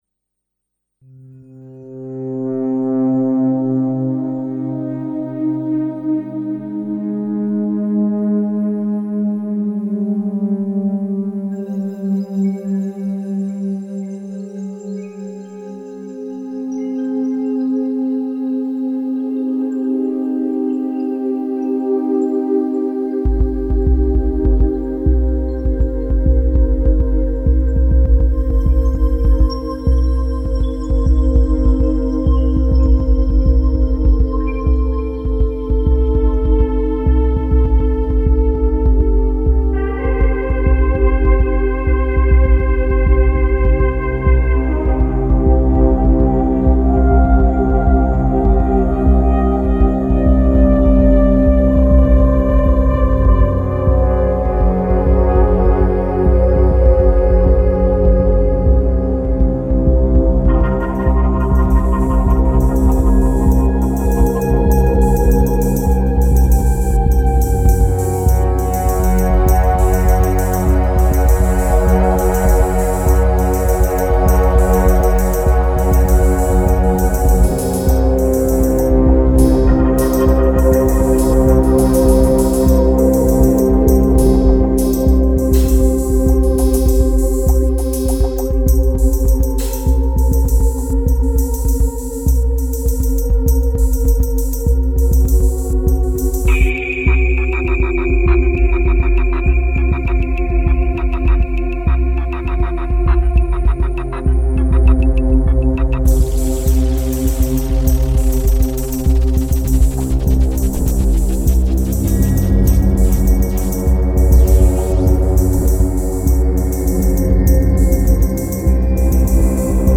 Dark ambient electronic